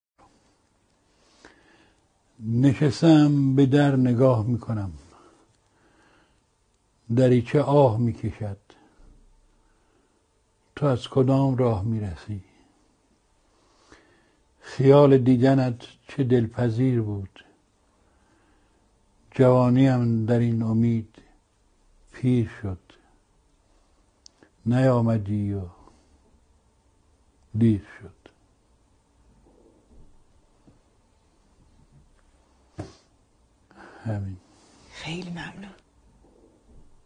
دکلمه آهنگ